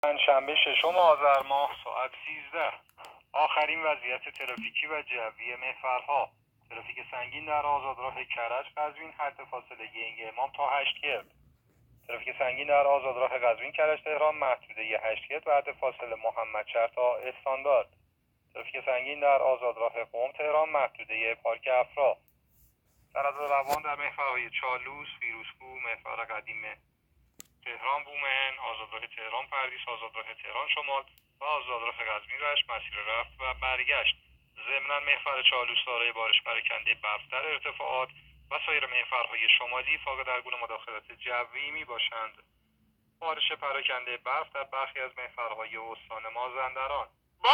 گزارش رادیو اینترنتی از آخرین وضعیت ترافیکی جاده‌ها ساعت ۱۳ ششم آذر؛